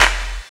[ACD] - StateProperty Clap.wav